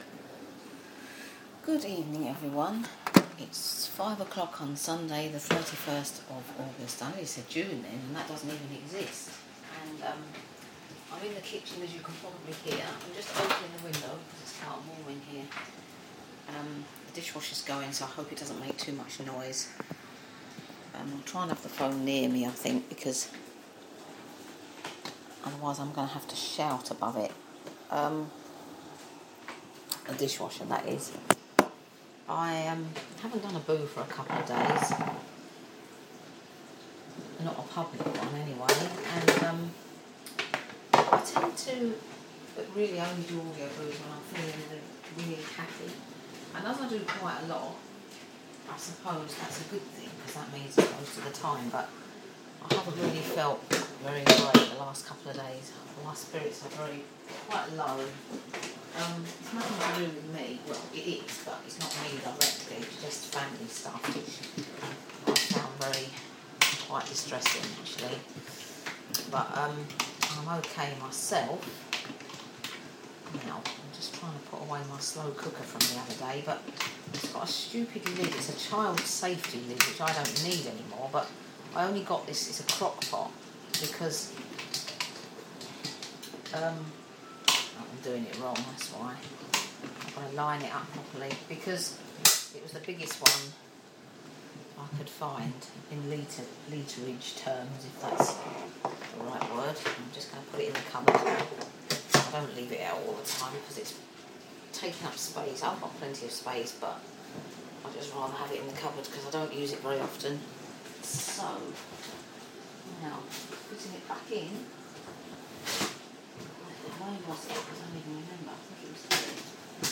A quick catch up while preparing dinner